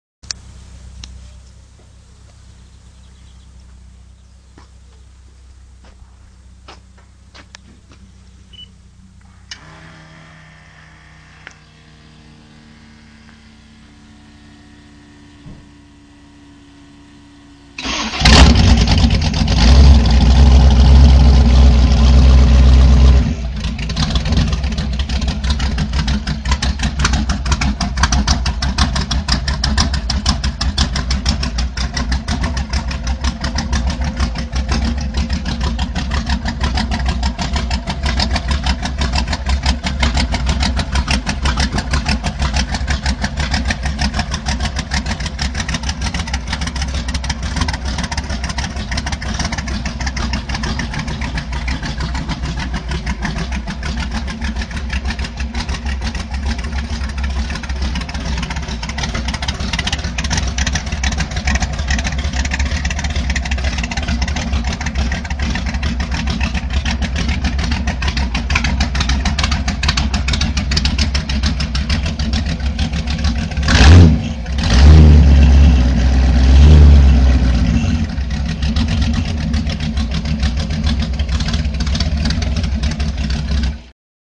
Hörrätsel
Welches Fahrzeug wird hier gestartet?
Fahrzeug.mp3